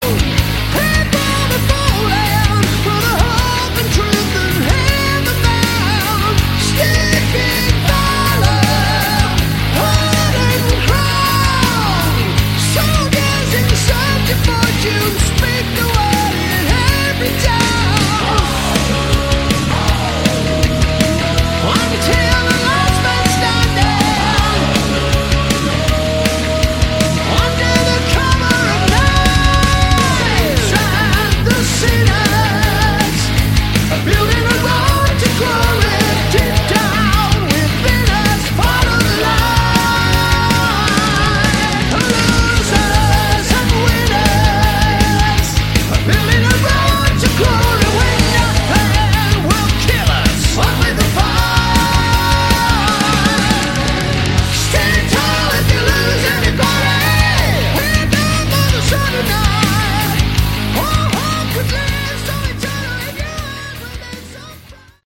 Category: Melodic Metal
vocals
drums
bass
guitar